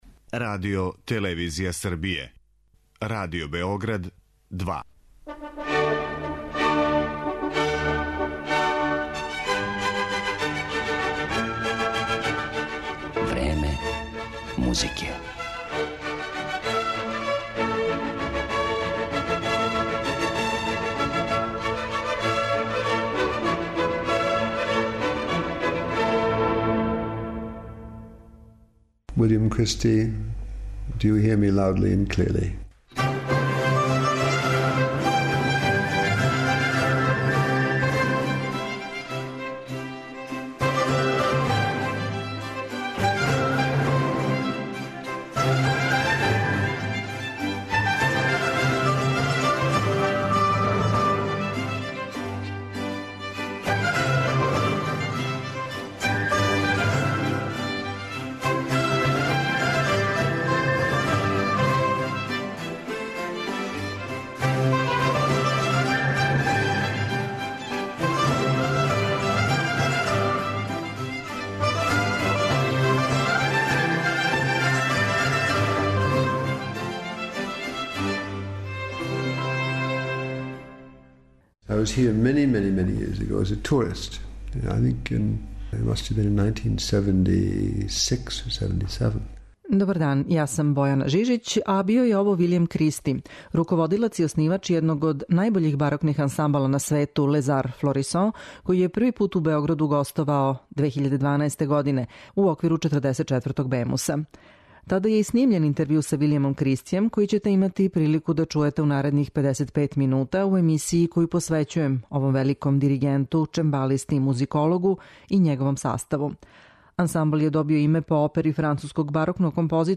У њој ћете имати екслузивну прилику да чујете и интервју остварен ca Виљемом Кристијем непосредно пред њихов први беогрaдски концерт.